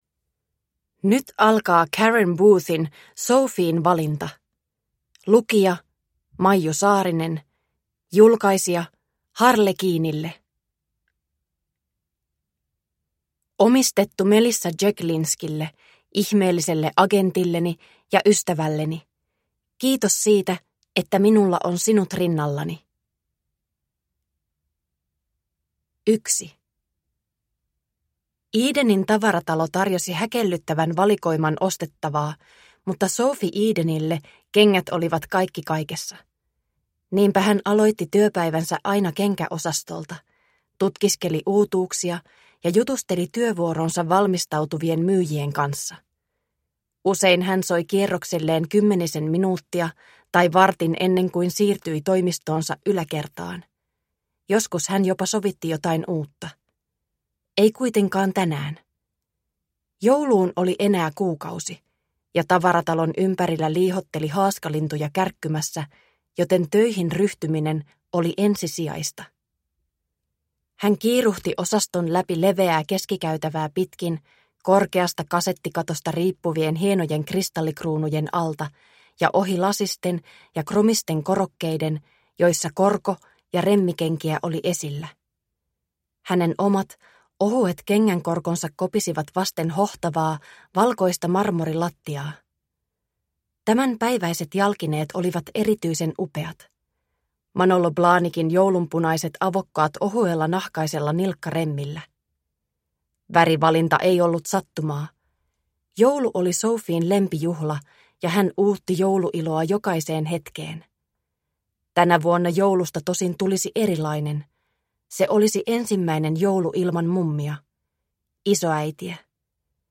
Sophien valinta – Ljudbok – Laddas ner